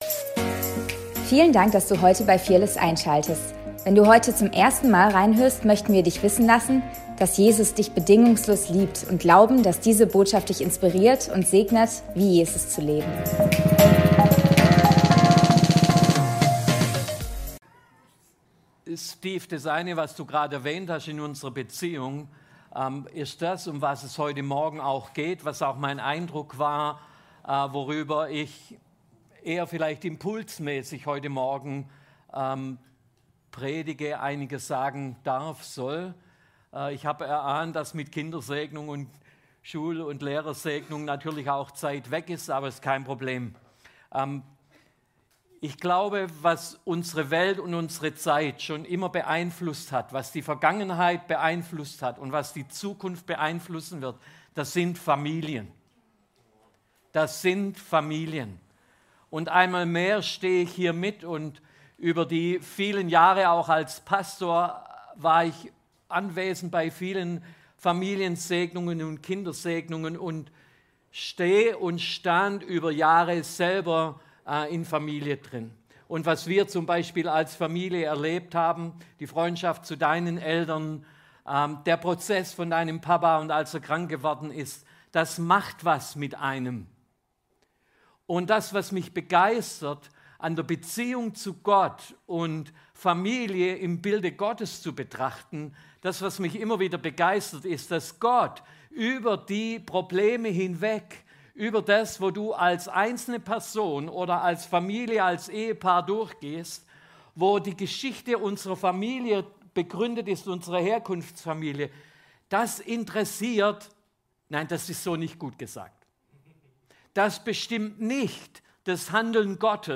Predigt vom 21.09.2025